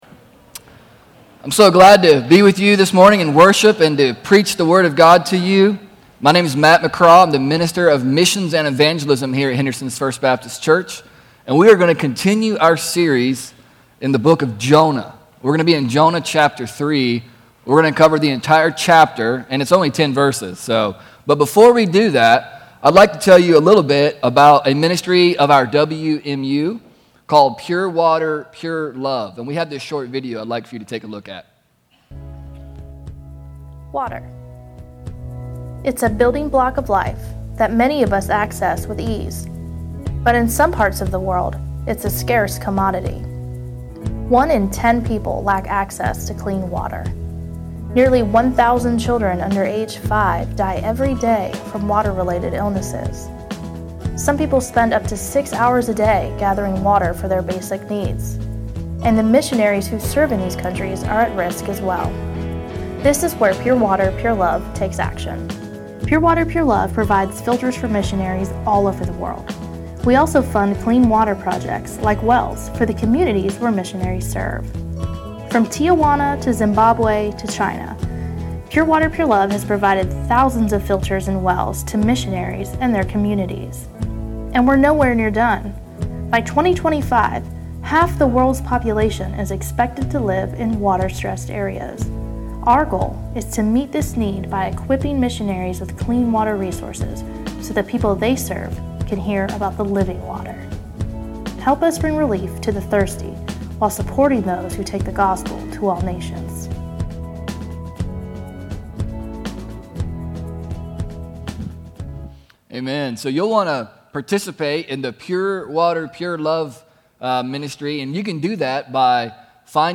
Before we get into the sermon